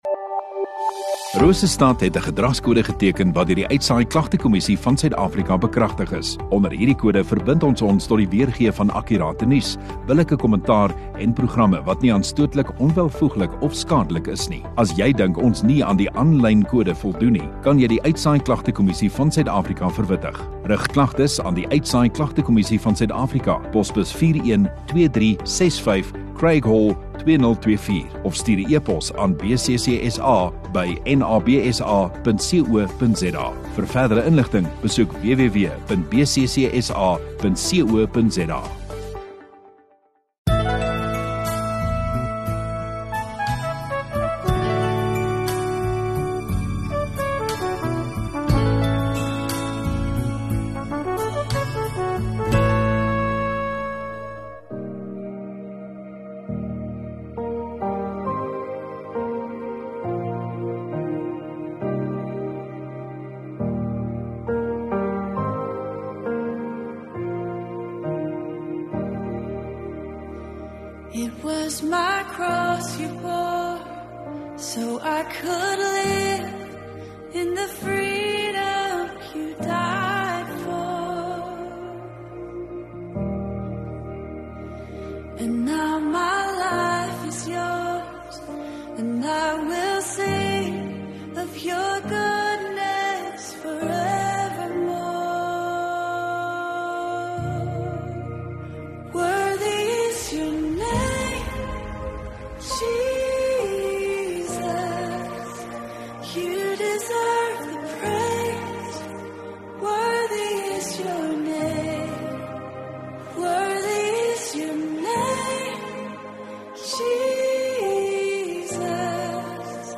8 Dec Sondagaand Erediens